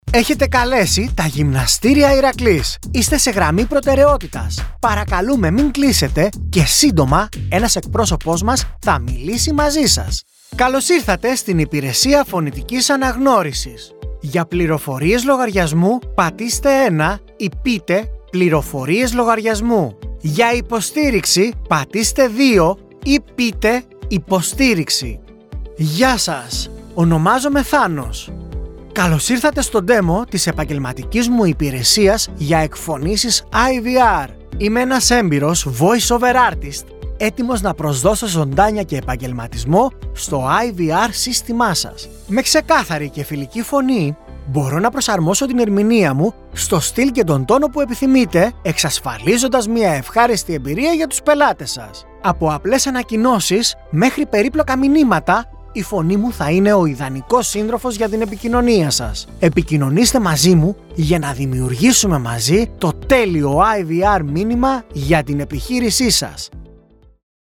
Male
Phone Greetings / On Hold
Greek Ivr Energetic / Friendly